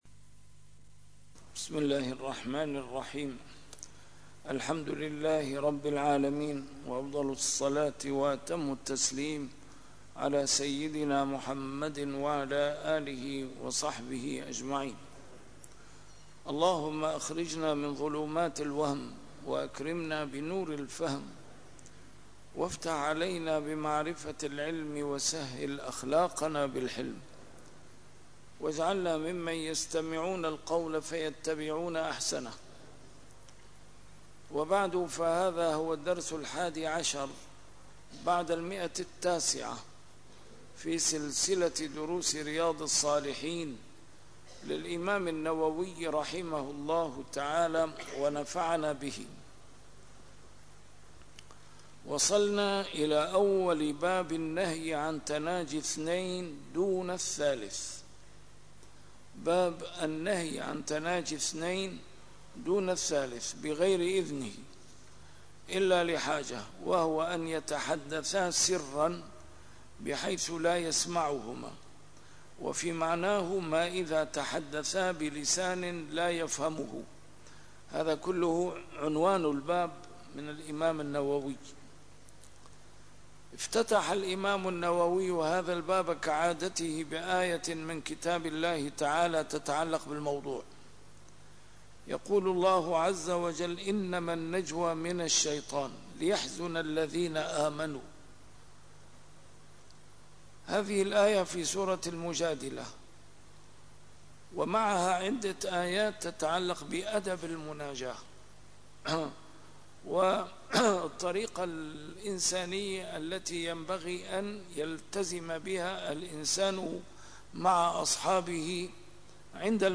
A MARTYR SCHOLAR: IMAM MUHAMMAD SAEED RAMADAN AL-BOUTI - الدروس العلمية - شرح كتاب رياض الصالحين - 911- شرح رياض الصالحين: النهي عن تناجي اثنين دون ثالث